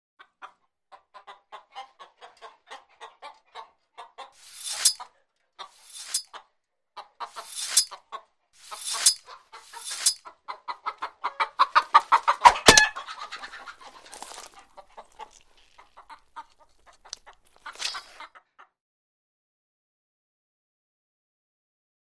Farmer Sharpens Knife And Chops Of Clucking Chicken's Little Head.